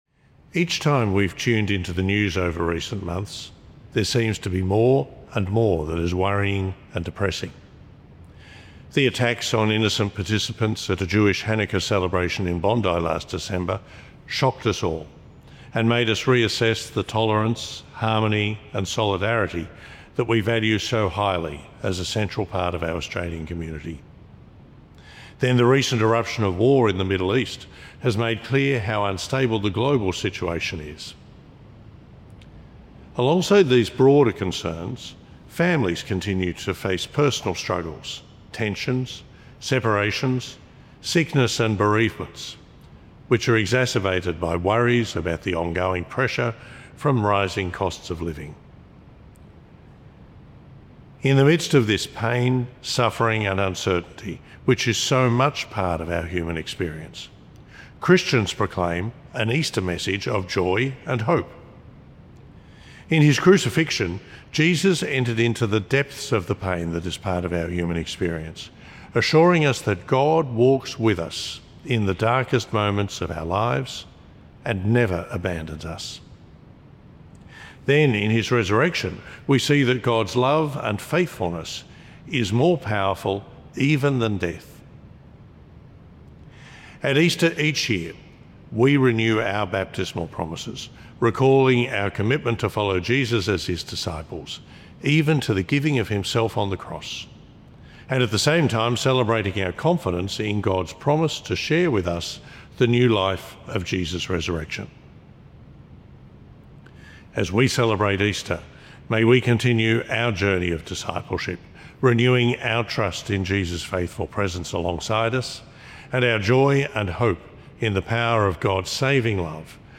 Archdiocese of Brisbane Easter Sunday - Two-Minute Homily: Archbishop Shane Mackinlay Apr 01 2026 | 00:02:05 1x 00:00 / 00:02:05 Subscribe Share RSS Feed Share Link Embed